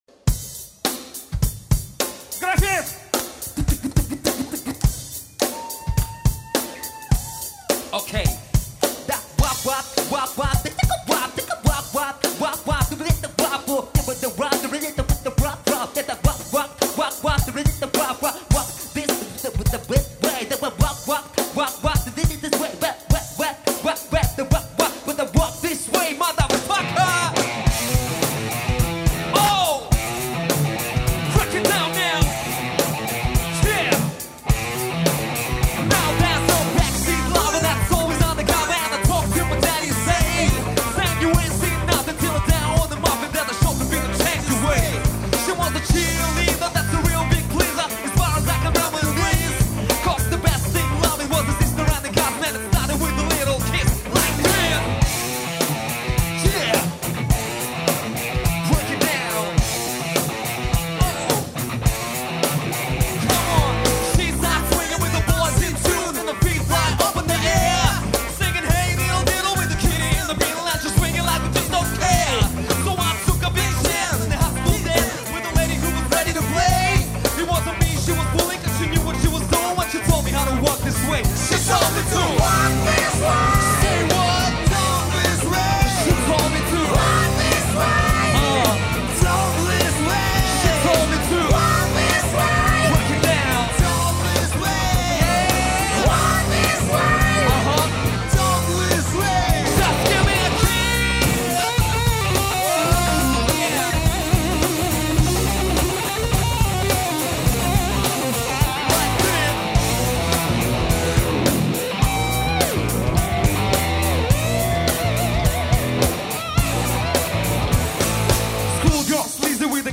в клубе Форте
Живее всех живых живую музыку играют)))